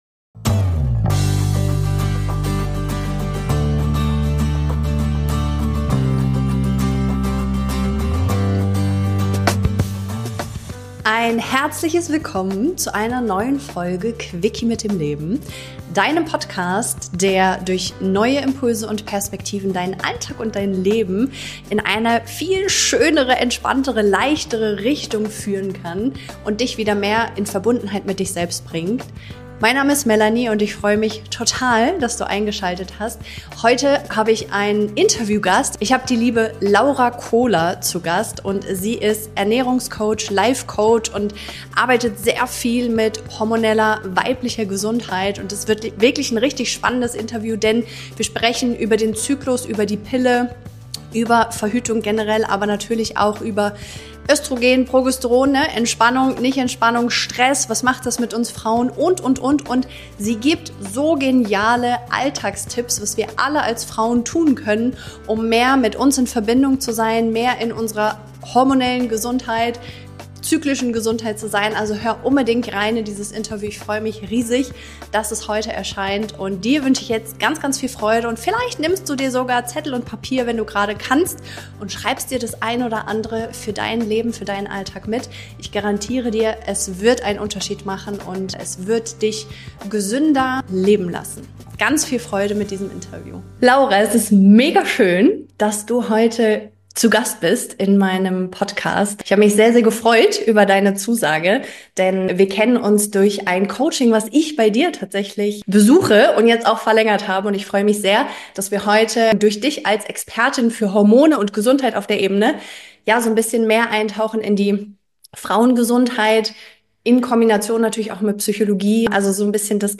Du erfährst, wie du deinen Körper besser verstehst, welche Blutwerte wirklich wichtig sind – und wie du Schritt für Schritt zurück in deine Energie findest. Ein Gespräch für Frauen, die mehr wollen als nur zu funktionieren – und bereit sind, sich selbst wieder zu vertrauen.